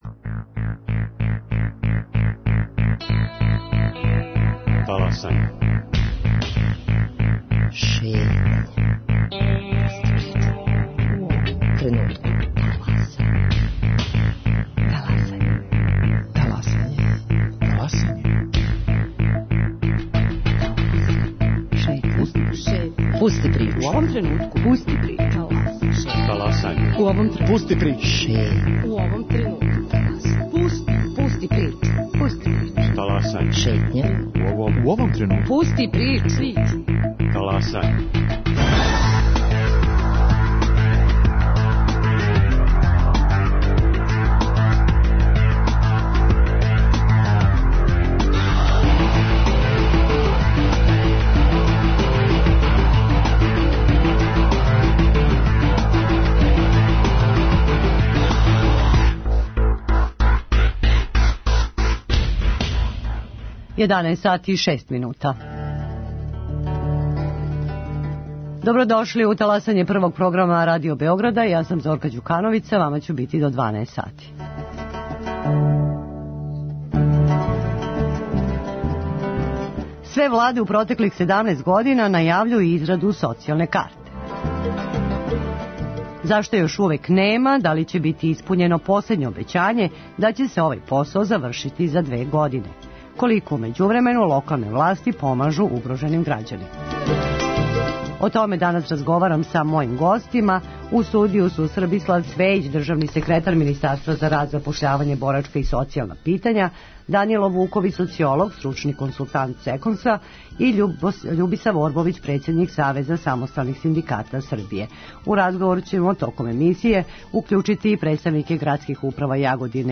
У разговор ће се укључити и представници градских управа Јагодине и Шапца